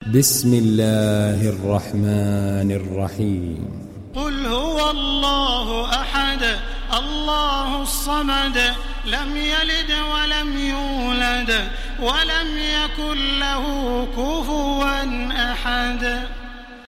Télécharger Sourate Al Ikhlas Taraweeh Makkah 1430